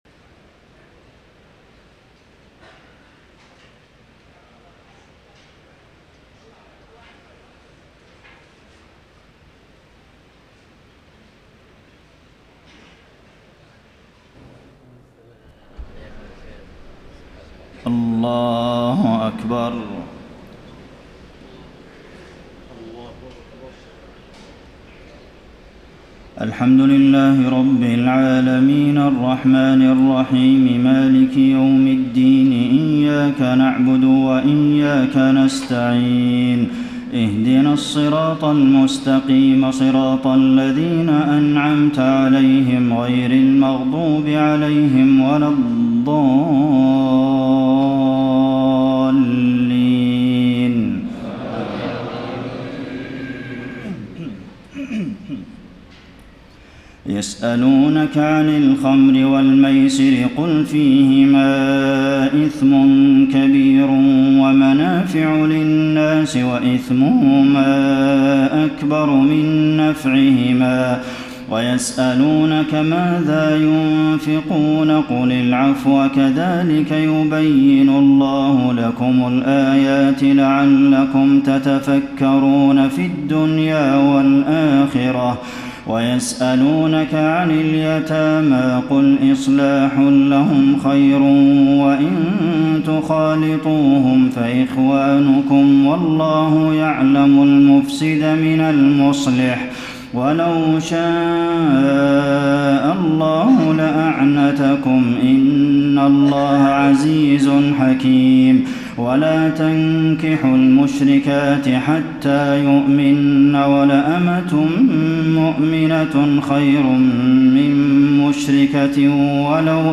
تهجد ليلة 22 رمضان 1433هـ من سورة البقرة (219-252) Tahajjud 22 st night Ramadan 1433H from Surah Al-Baqara > تراويح الحرم النبوي عام 1433 🕌 > التراويح - تلاوات الحرمين